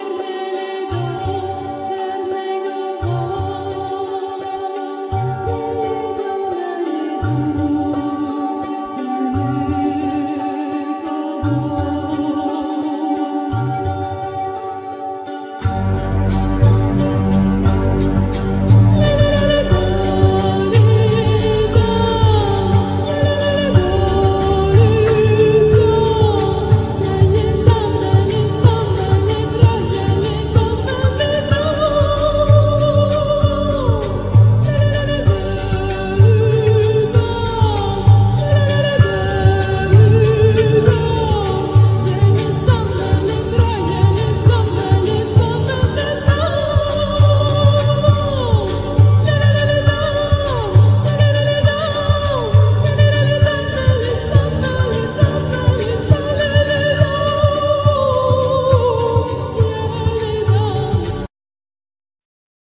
Voice
Santoor,Balalaika,Mandolin,Keyboards,Dulcimer,Shaker,